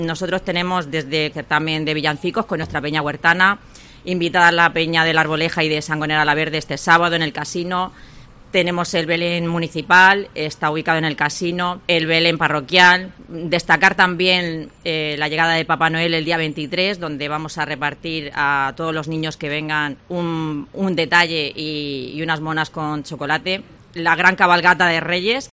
Verónica Sánchez, pedánea de El Palmar